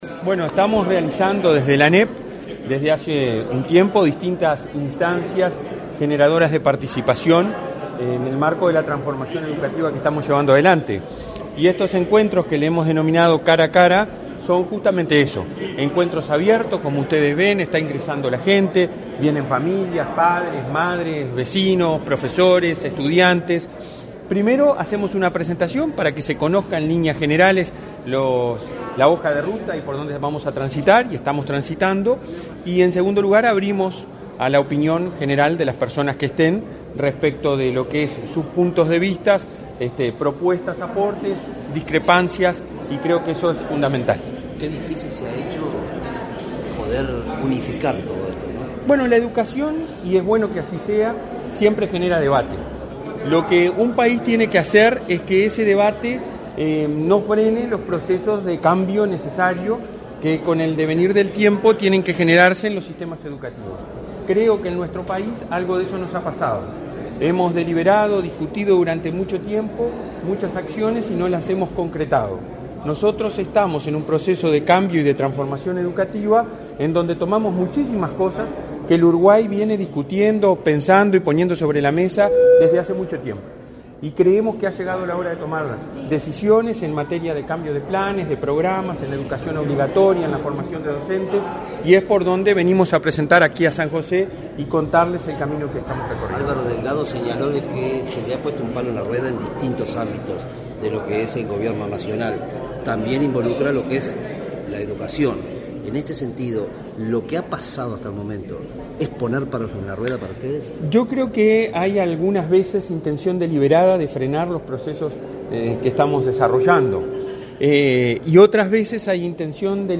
Declaraciones a la prensa del presidente del Codicen de la ANEP, Robert Silva, en San José
Declaraciones a la prensa del presidente del Codicen de la ANEP, Robert Silva, en San José 16/09/2022 Compartir Facebook X Copiar enlace WhatsApp LinkedIn Este 16 de setiembre se realizó un nuevo encuentro Cara a Cara con la Comunidad, esta vez en San José, donde presentó los ejes estratégicos de la transformación educativa. El presidente del Consejo Directivo Central (Codicen) de la Administración Nacional de Educación Pública (ANEP), Robert Silva, dialogó con la prensa.